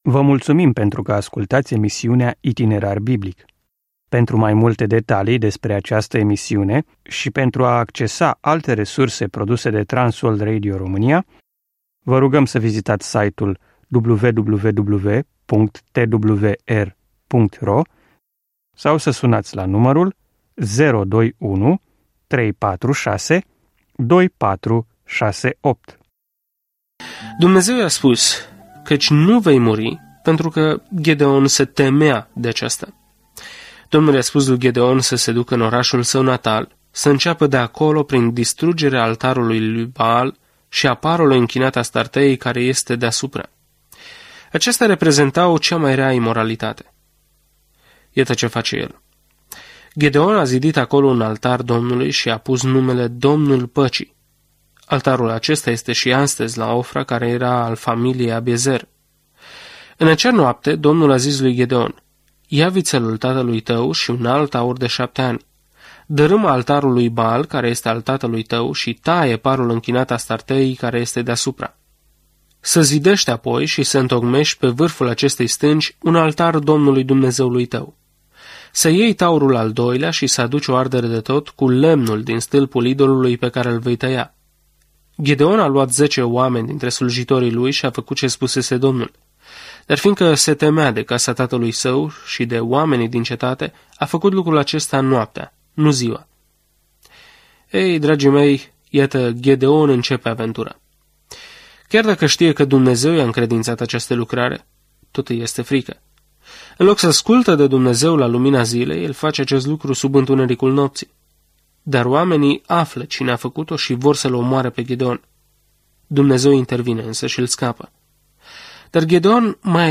Scriptura Judecătorii 6:23-40 Judecătorii 7:1-22 Ziua 4 Începe acest plan Ziua 6 Despre acest plan Judecătorii înregistrează viețile uneori întortocheate și pe dos ale oamenilor care se instalează în noua lor viață în Israel. Călătoriți zilnic prin Judecători în timp ce ascultați studiul audio și citiți versete selectate din Cuvântul lui Dumnezeu.